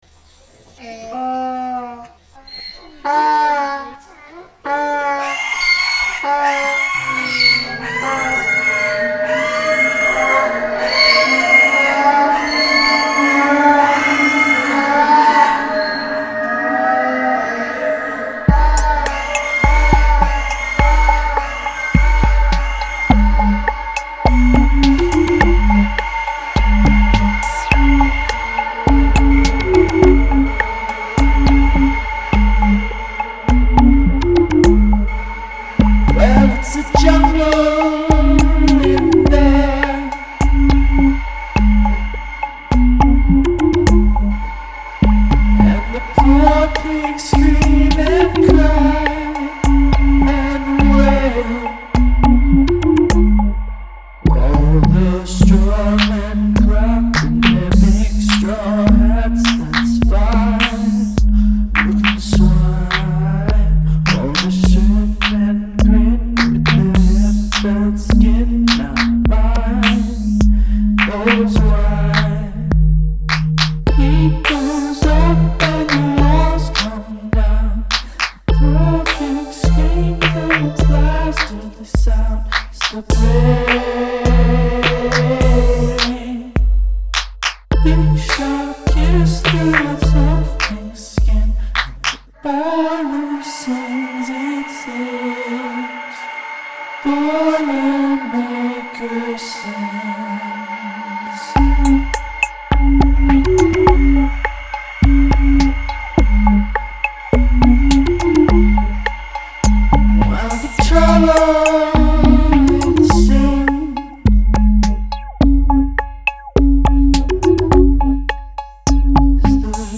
Integrate animal sounds into your song's structure